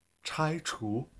口音有声数据
口音（男声）